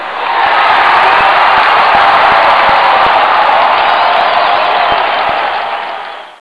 CHEER.WAV